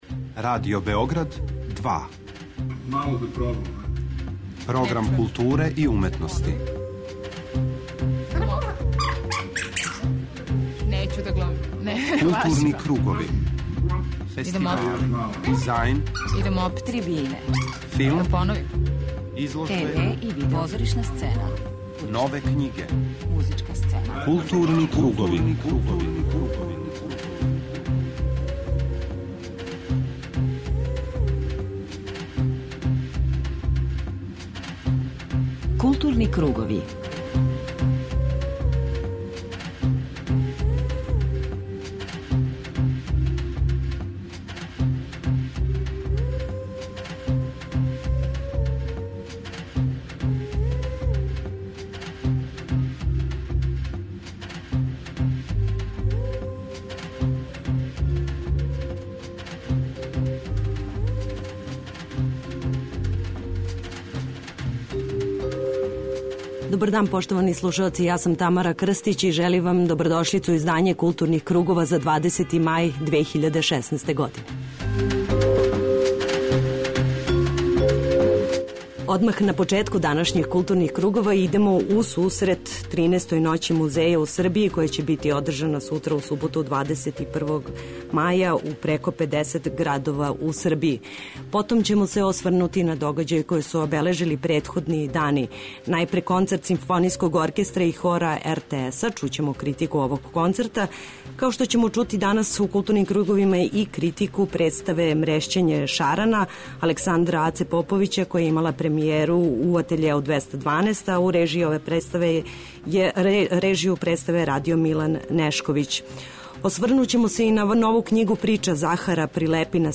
преузми : 41.19 MB Културни кругови Autor: Група аутора Централна културно-уметничка емисија Радио Београда 2.